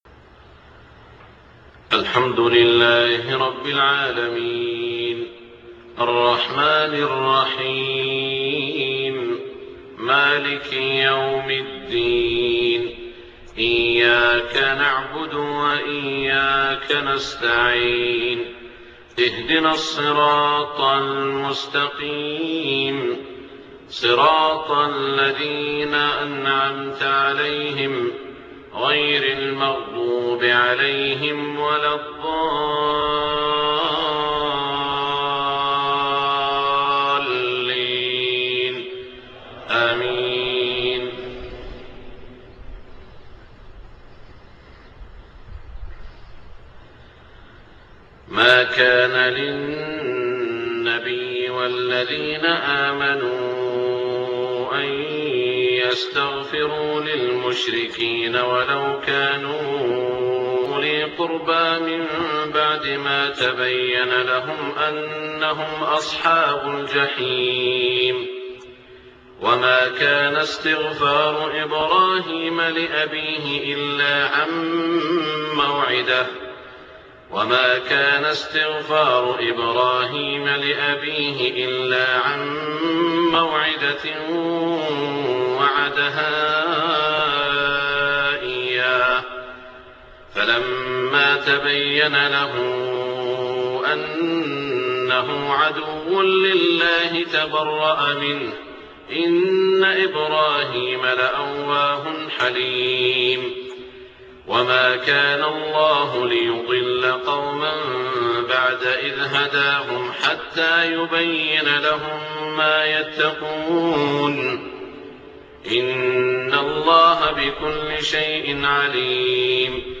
صلاة الفجر 3-7-1426 من سورة التوبة > 1426 🕋 > الفروض - تلاوات الحرمين